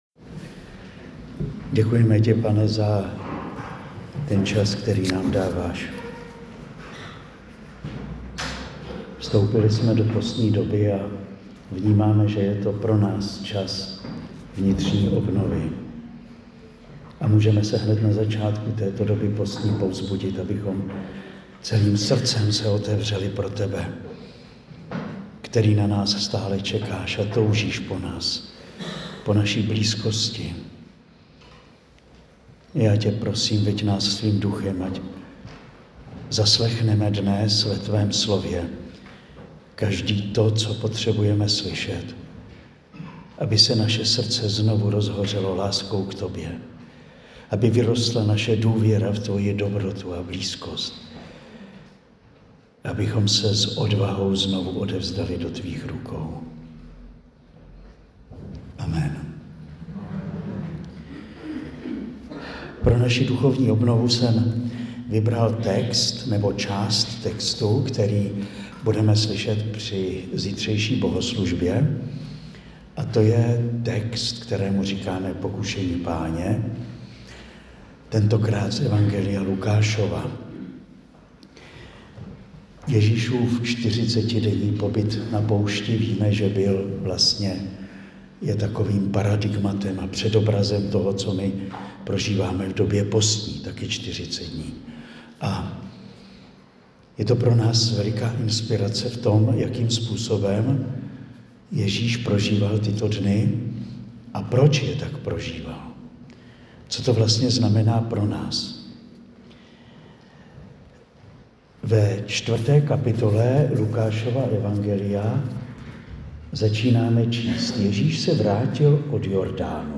Promluva zazněla na postní duchovní obnově v Místku dne 8. 3. 2025